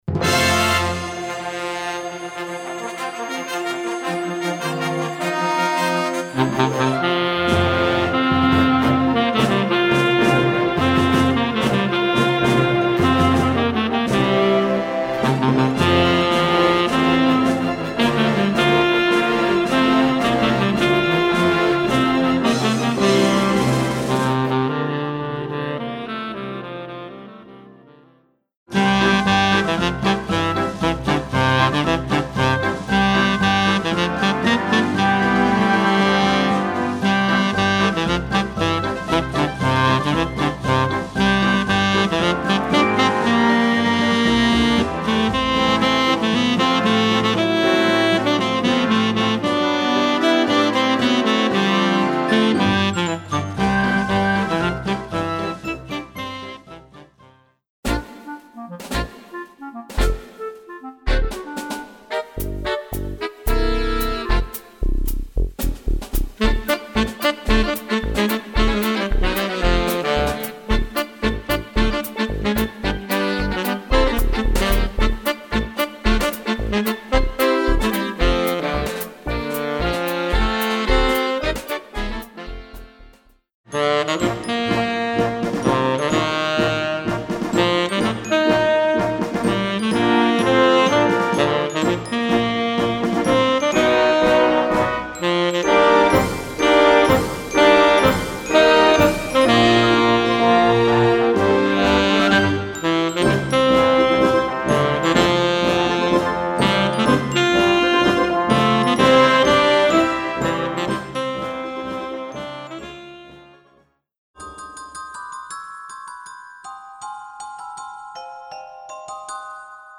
Voicing: Tenor Saxophone w/ Audio